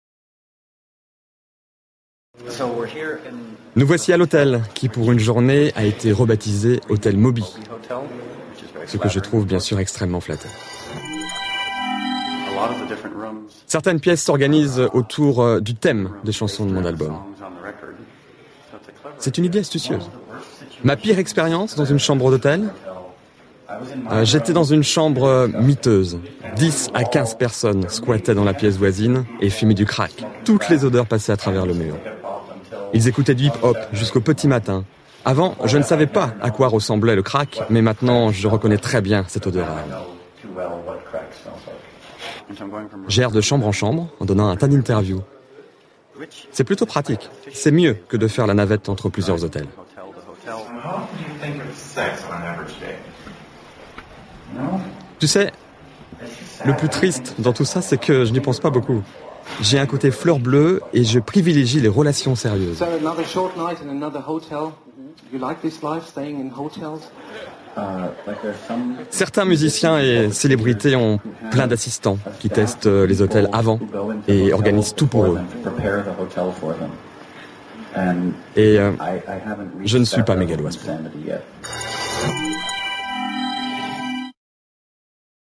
Sprecher französisch.
Sprechprobe: Sonstiges (Muttersprache):
French voice over talent.